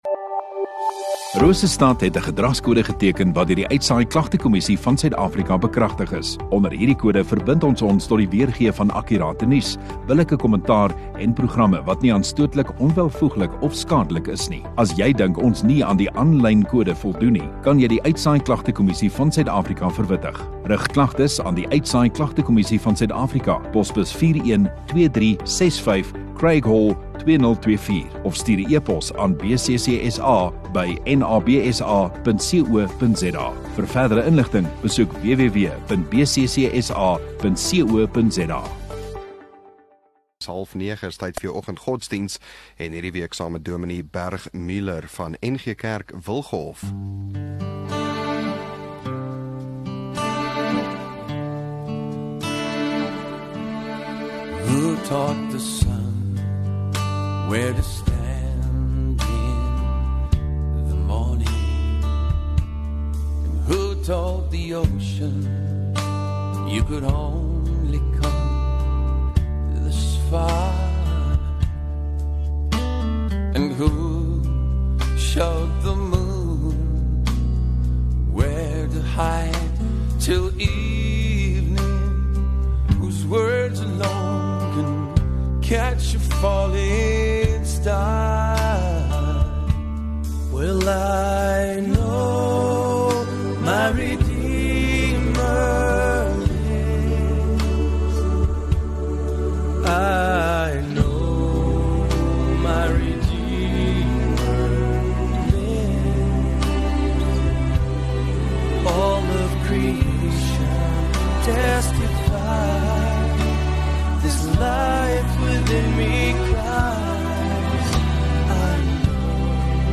16 Jul Woensdag Oggenddiens